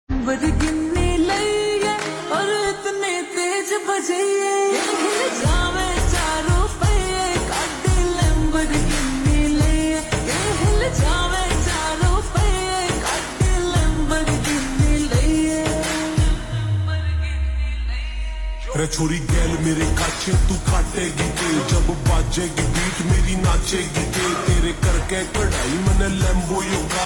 Haryanvi Songs
(Slowed + Reverb)